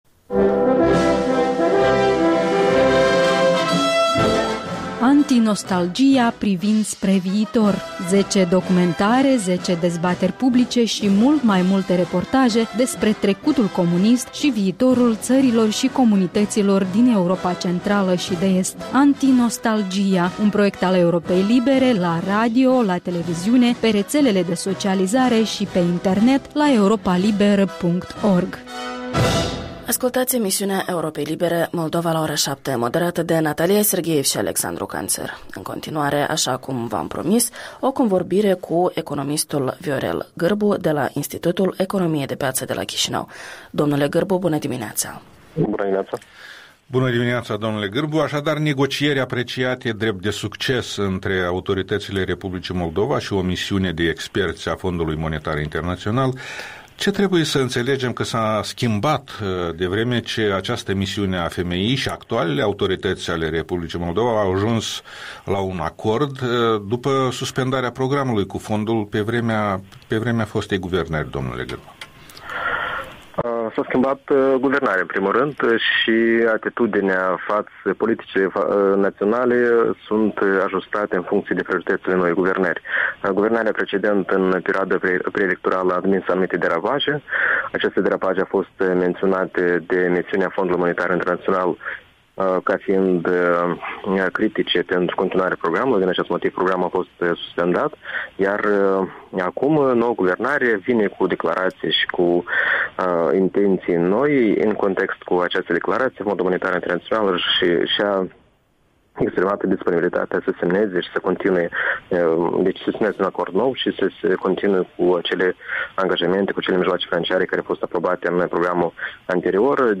Interviul dimineții.